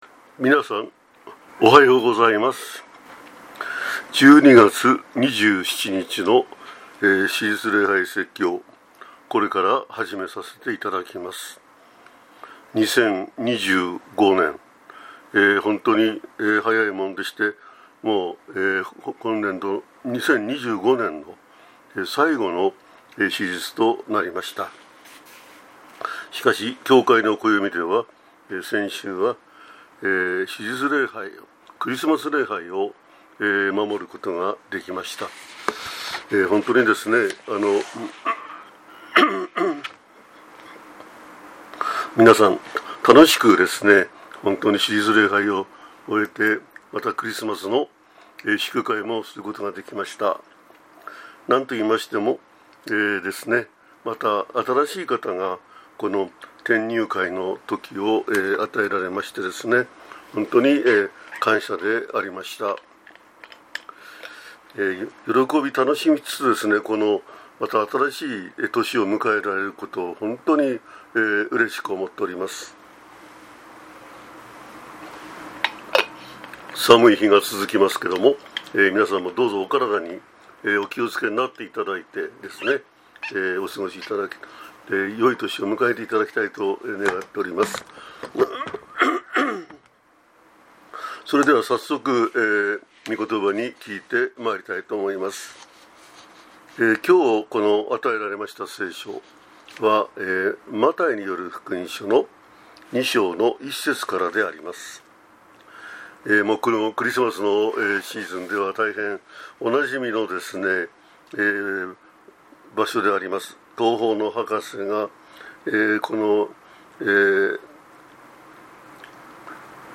説教 信じる者が与えられた